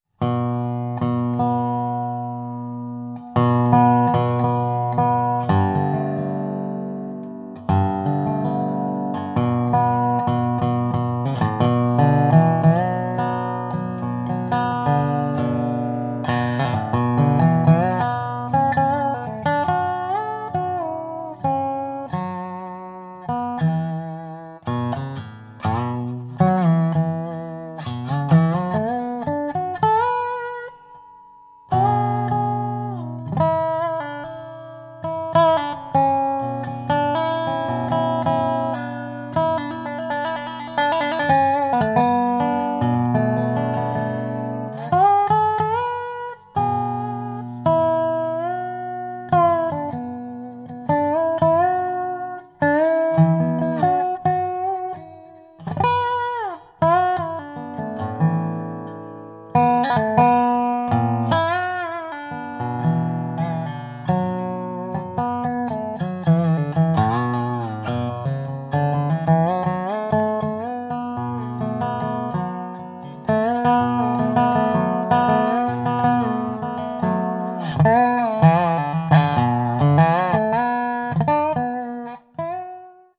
Díky smrkové přední desce je zvuk nástroje jemný a měkký. Tento nástroj se může pochlubit výbornou vyvážeností celkového zvuku.
Ve vysokých polohách je barva zvuku příjemně zakulacená a konkrétní. Celkový zvuk je silný, barevně prokreslený a pestrý.